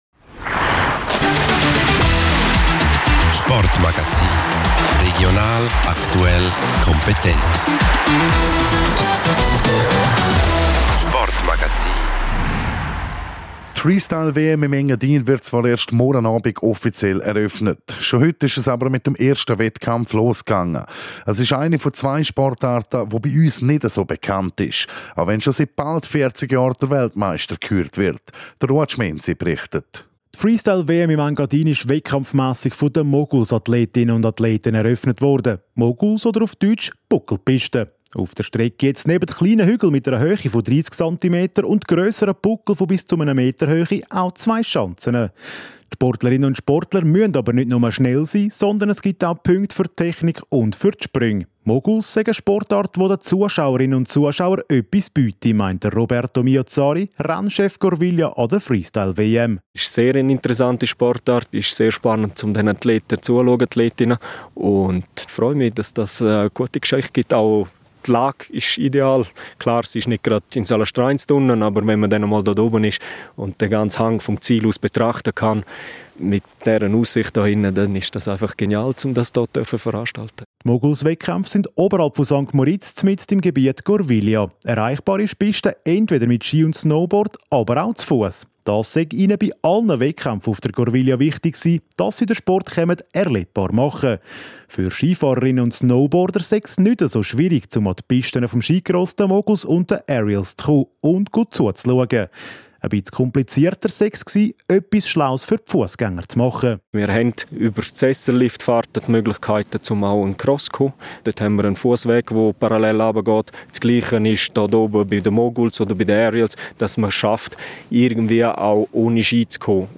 Sport Magazin